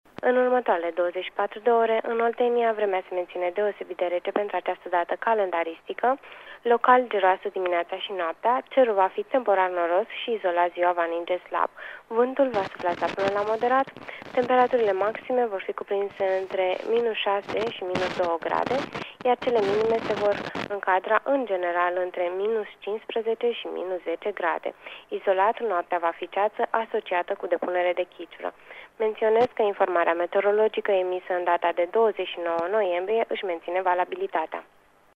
Meteo
Prognoza meteo 30 noiembrie (audio)